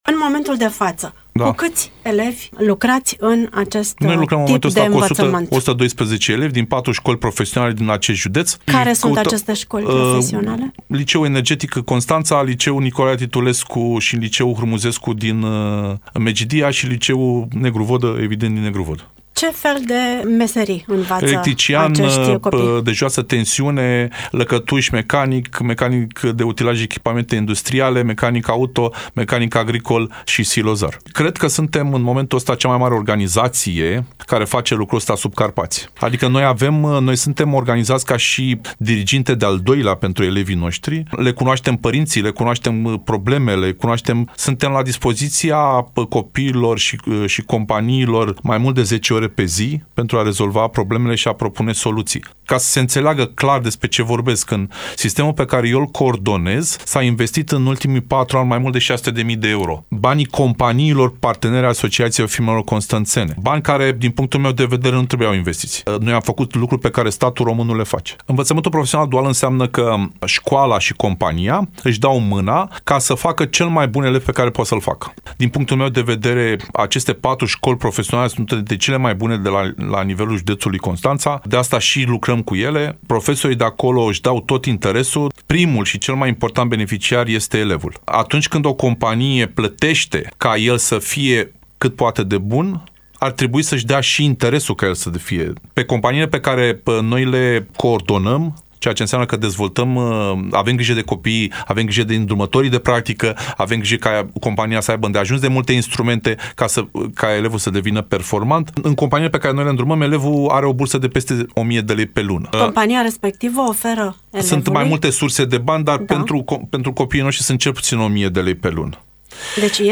în emisiunea „Dialoguri la zi” de astăzi.